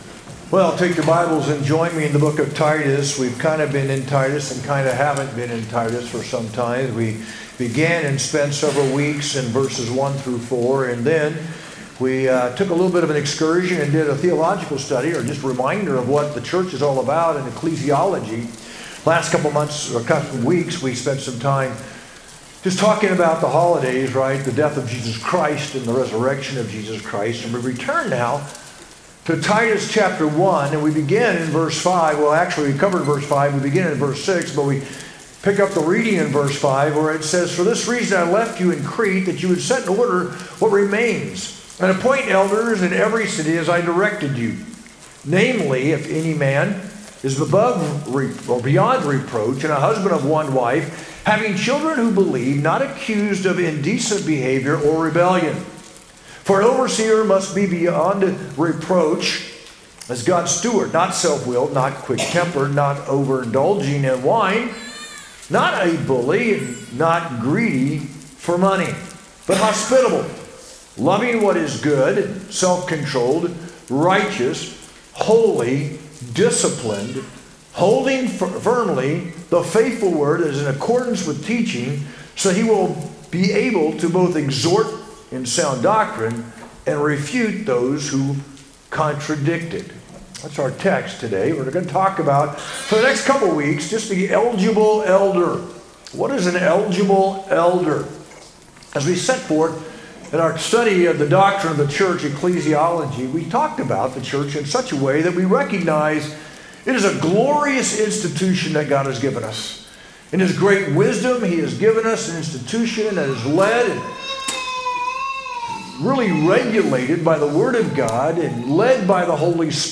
sermon-4-27-25.mp3